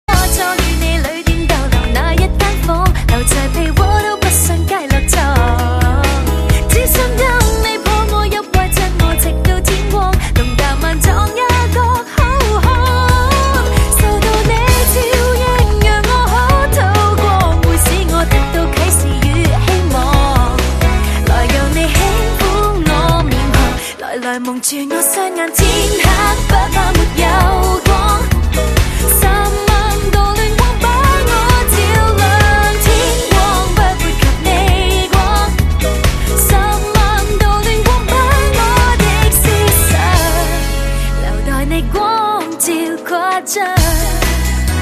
M4R铃声, MP3铃声, 华语歌曲 131 首发日期：2018-05-16 00:45 星期三